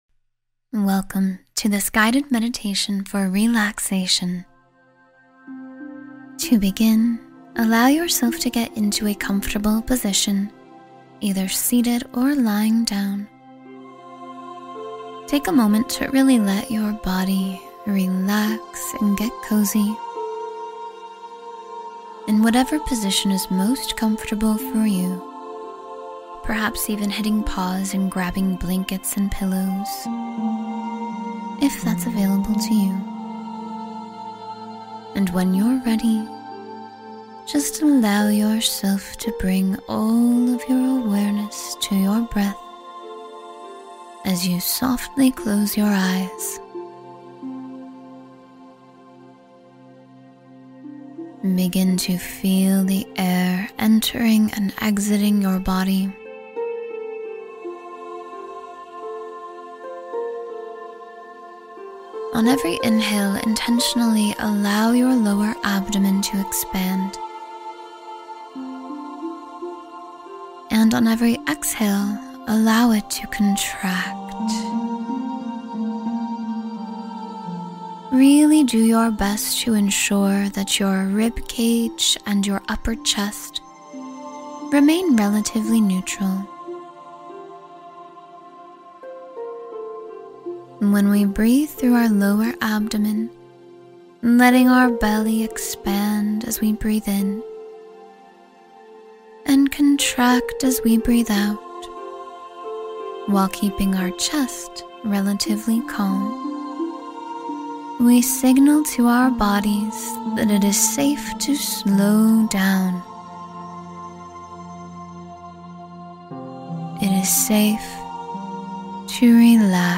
Deep Relaxation for Mind and Body Calm — Guided Meditation for Total Relaxation